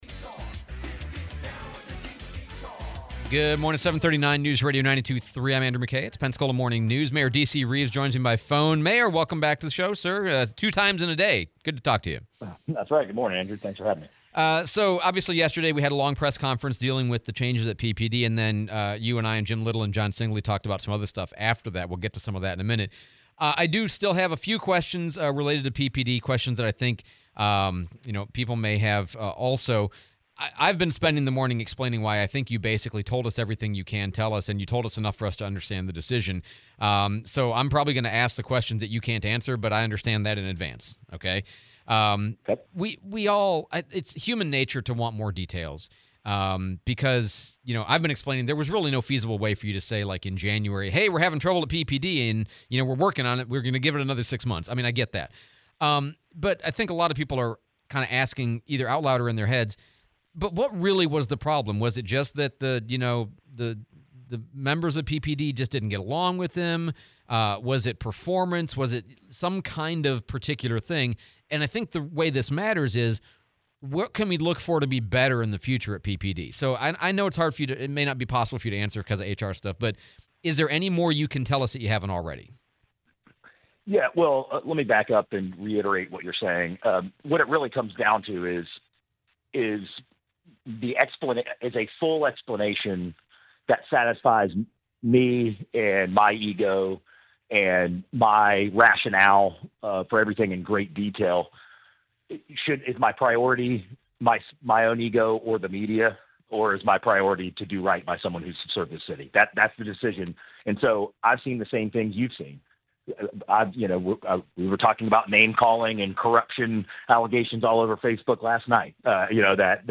07/16/2025 Interview with Mayor DC Reeves